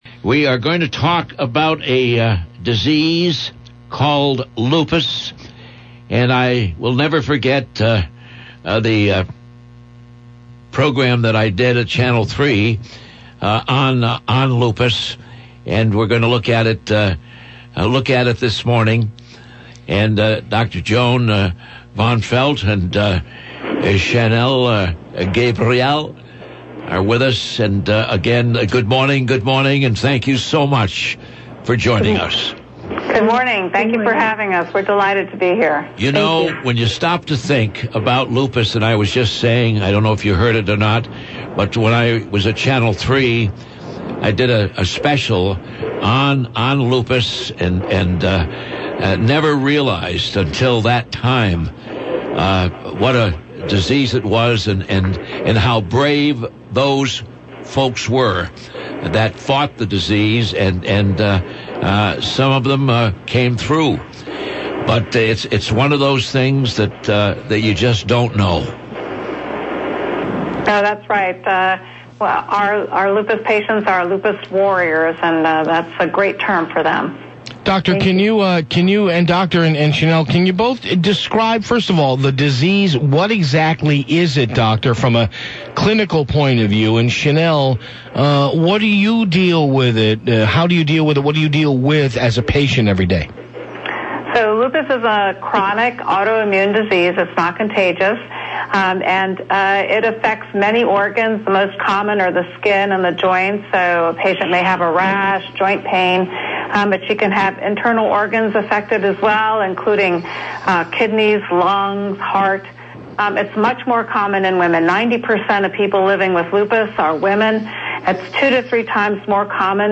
Lupus Interview